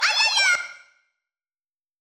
failsound.wav